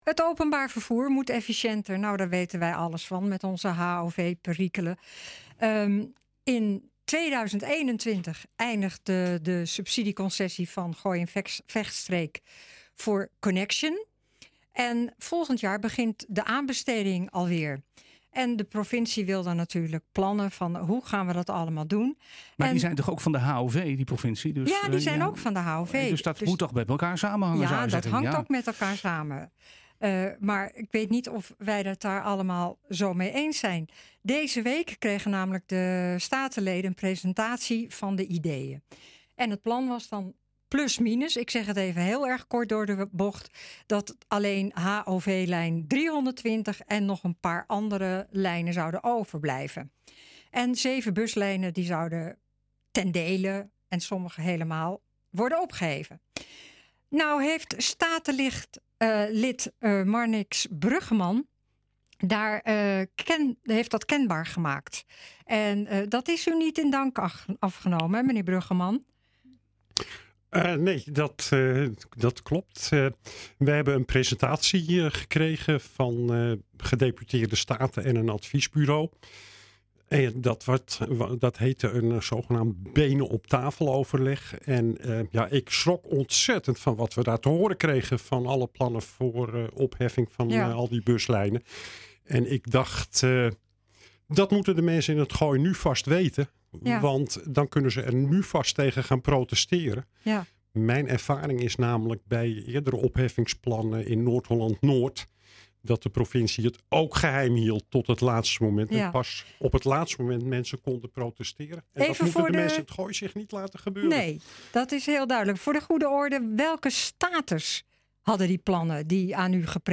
Zeven buslijnen zouden geheel of gedeeltelijk worden opgeheven. Statenlid Marnix Bruggeman was bij de presentatie aanwezig en nu in de studio voor een toelichting.